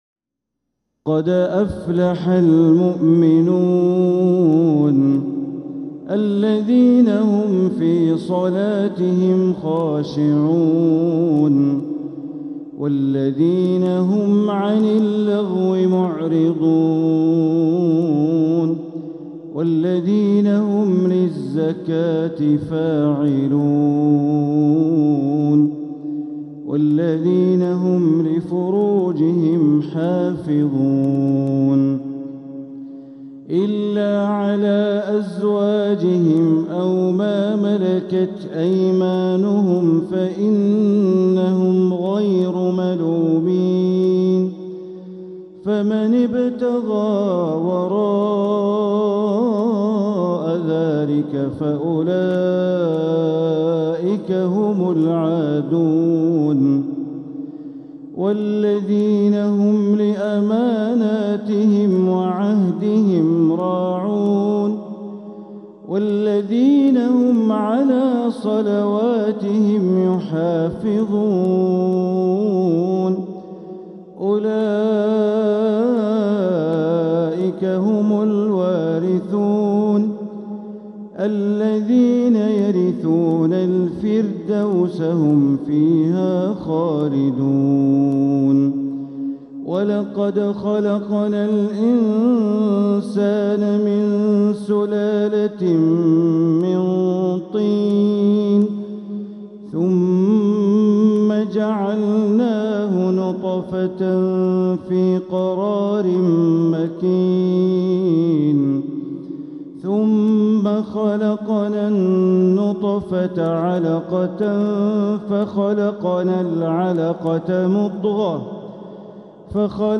سورة المؤمنون كاملة بترتيل تدبري خاشع للشيخ د. بندر بليلة | من فجريات شهر محرم 1447هـ > السور المكتملة من الحرم > المزيد - تلاوات بندر بليلة